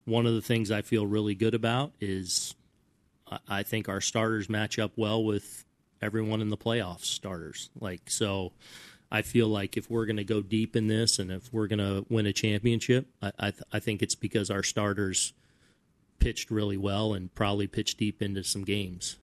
Aaron Boone spoke highly of not just Cole, but the entire rotation. Here’s what he had to say on how the Yankees should match up against the Guardians on the mound.